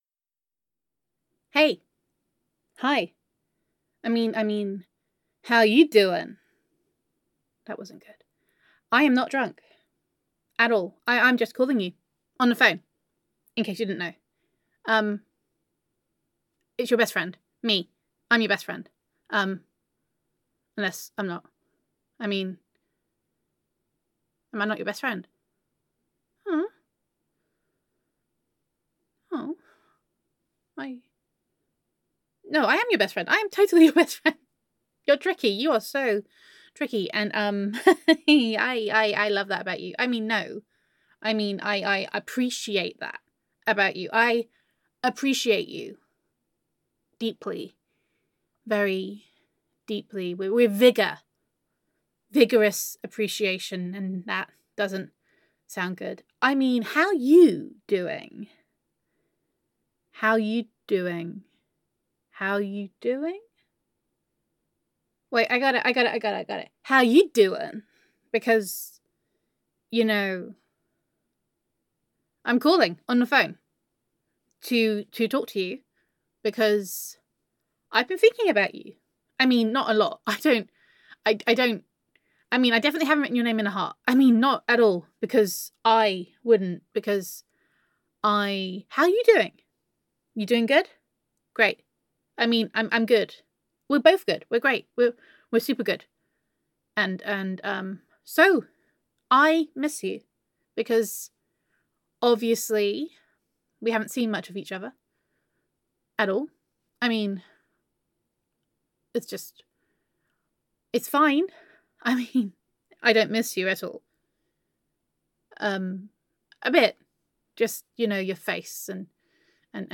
[F4A] I Am a Donut [Not a Tree][I Am a Cactus][I Am Absolutely Not Drunk][Where Did All the Rum Go][Voicemail][Gender Neutral][Drunken Best Friend Love Confession]